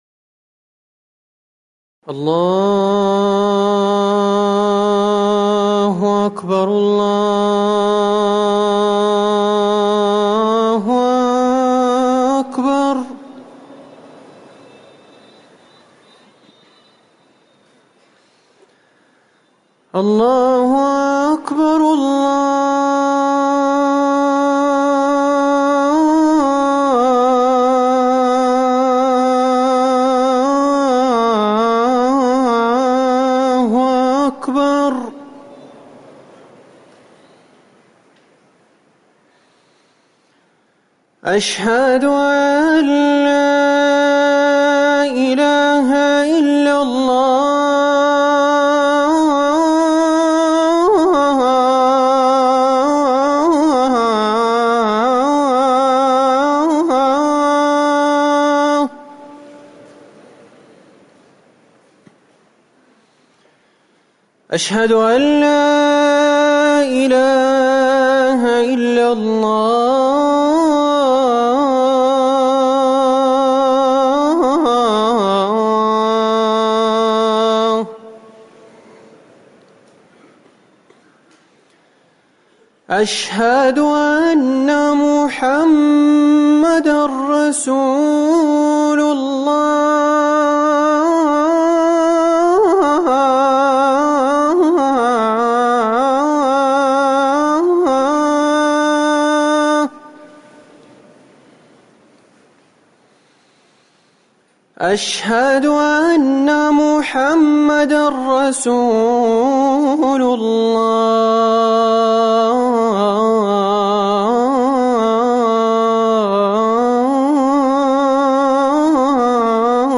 أذان العشاء
تاريخ النشر ٢٢ محرم ١٤٤١ هـ المكان: المسجد النبوي الشيخ